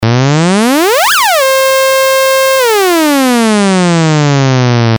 pic 11a)-c): a saw waveform a)nonmodulated, b)amplitudemodulated and c) pitchmodulated.
pitchmod_saw.mp3